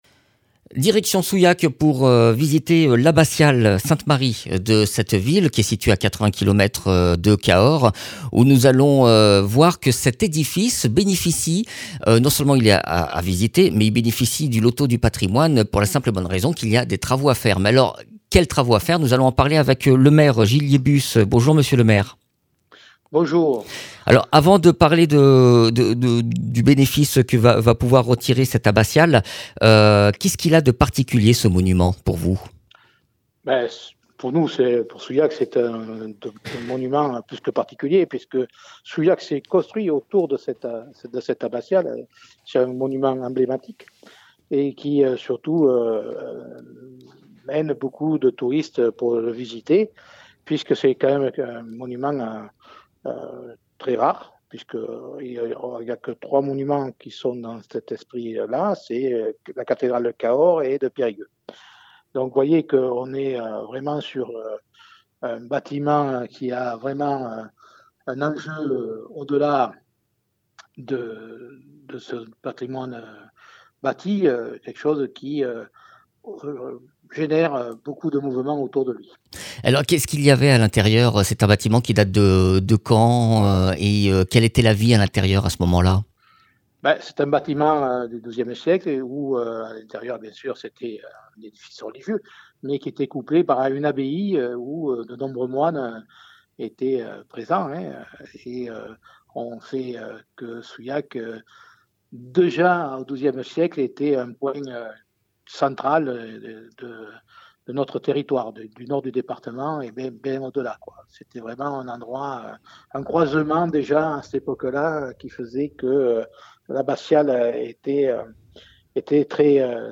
Interviews
Invité(s) : Gilles Liébus, maire de Souillac